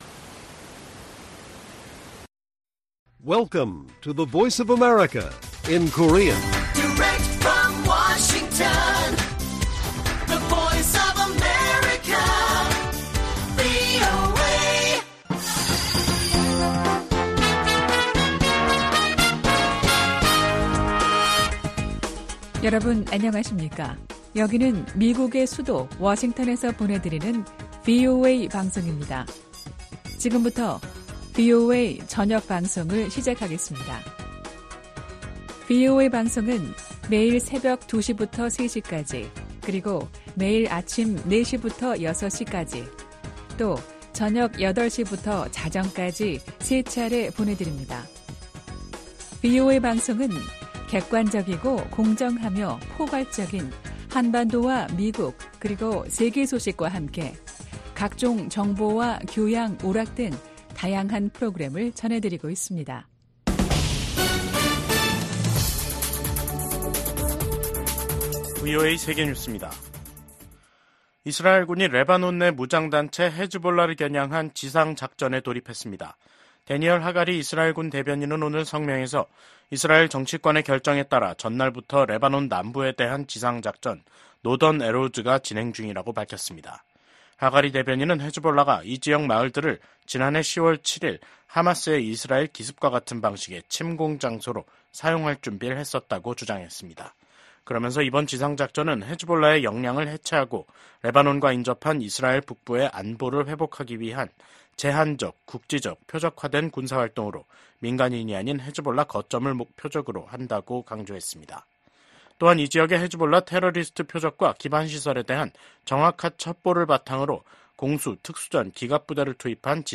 VOA 한국어 간판 뉴스 프로그램 '뉴스 투데이', 2024년 10월 1일 1부 방송입니다. 윤석열 한국 대통령은 북한이 핵무기를 사용하려 한다면 정권 종말을 맞게 될 것이라고 경고했습니다. 김성 유엔주재 북한 대사가 북한의 핵무기는 자위권을 위한 수단이며 미국과 핵 문제를 놓고 협상하지 않겠다고 밝혔습니다. 신임 일본 총리 예정자가 ‘아시아판 나토’ 창설 필요성을 제기한 가운데, 백악관은 기존 북대서양조약기구 강화에 주력하고 있음을 강조했습니다.